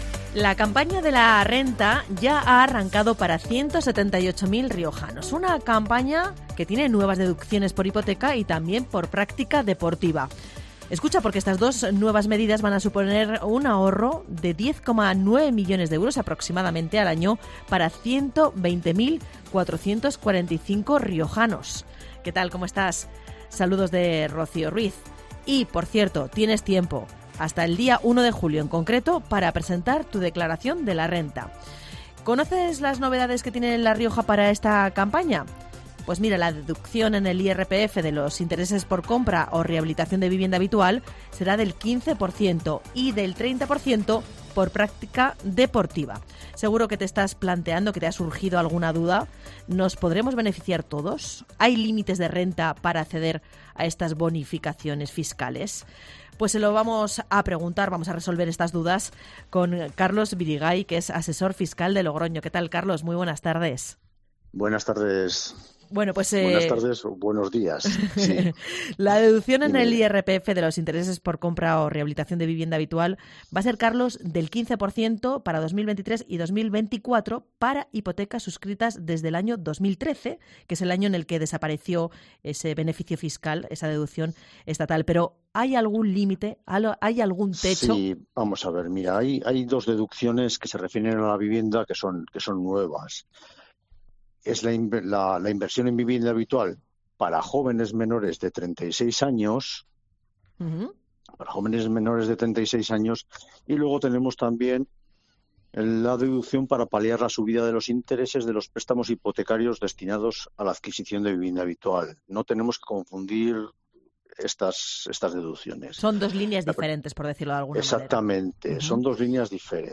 Declaración de la Renta: Un asesor fiscal resuelve las dudas para desgravar